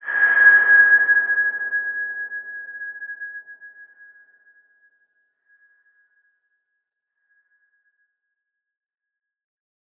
X_BasicBells-G#4-mf.wav